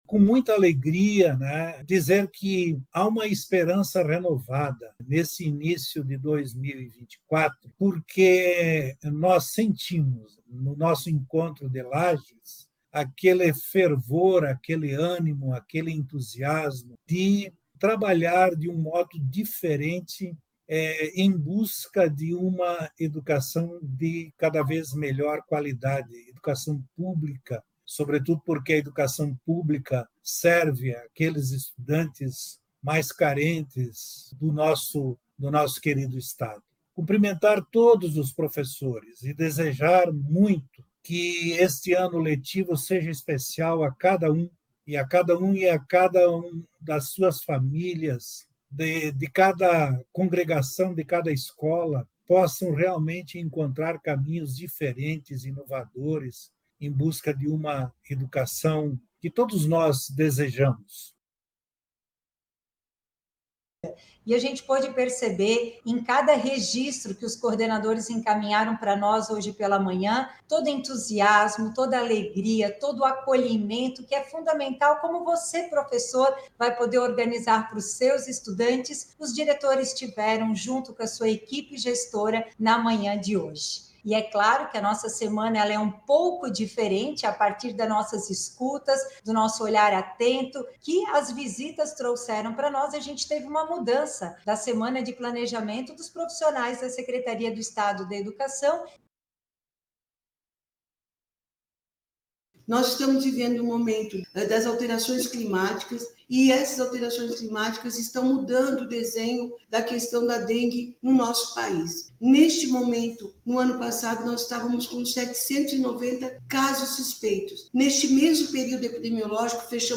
O secretário da pasta, Aristides Cimadon, falou sobre os preparativos e a expectativa para o próximo ano letivo:
Já a secretária adjunta de Estado da Educação, Patrícia Lueders, ressaltou que o novo formato busca aproximar ainda mais a SED de quem vive o dia a dia das escolas escutando as observações dos profissionais da educação:
A secretária de Estado da Saúde, Carmen Zanotto, participou do evento e alertou para a importância de trabalhar a prevenção contra a doença no ambiente escolar: